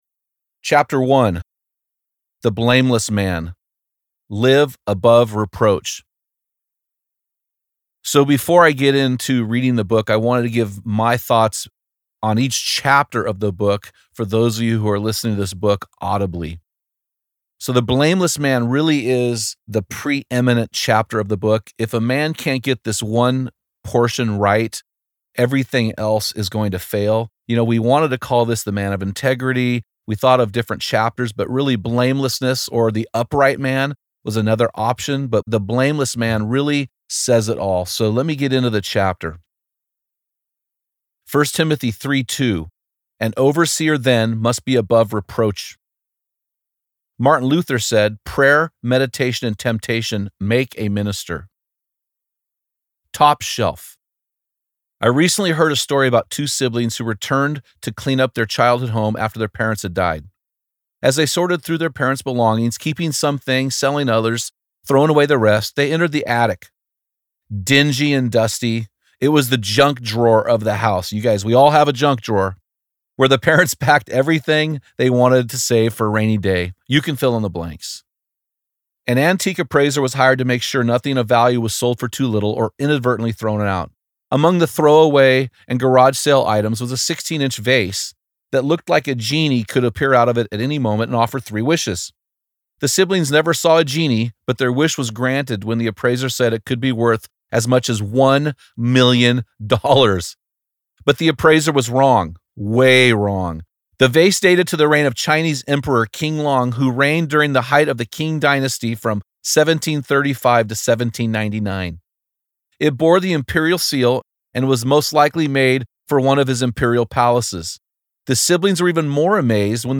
Audio Book Sample